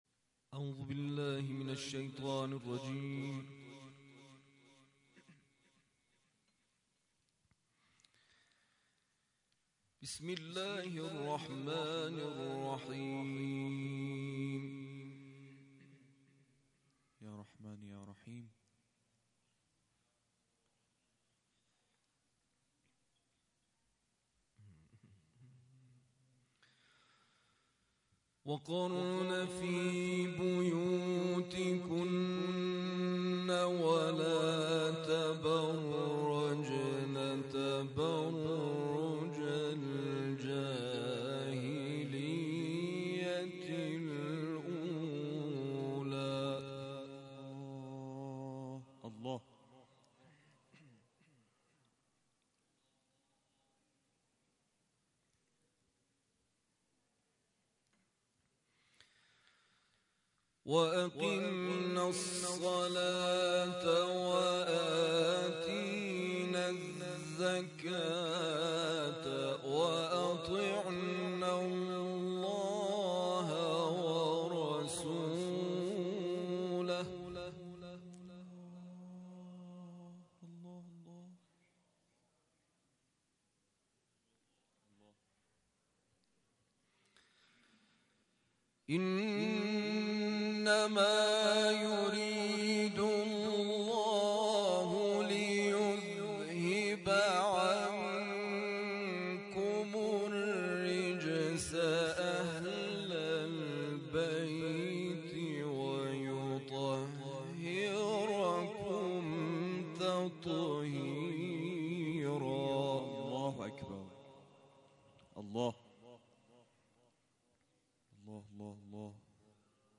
تلاوت
در مسجد جامع رسول اکرم(ص) واقع در تهرانسر، شهرک صدف